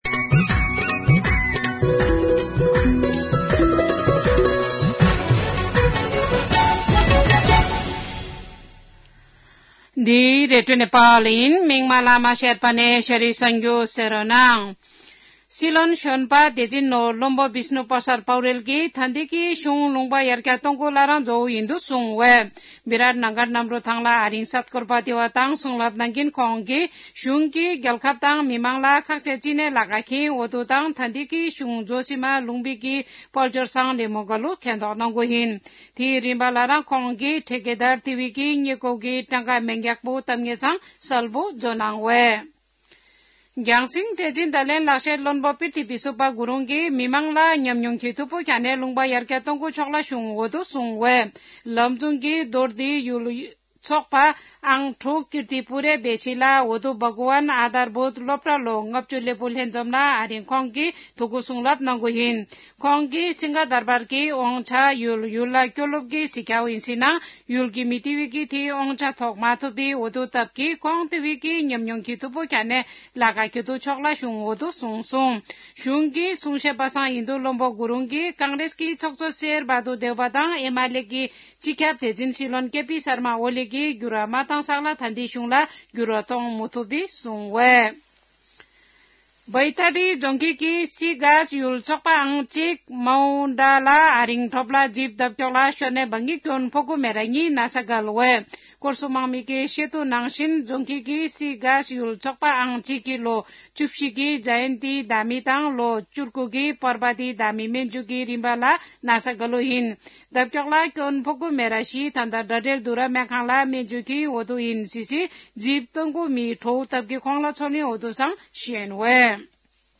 शेर्पा भाषाको समाचार : १३ माघ , २०८१
Sherpa-News-7.mp3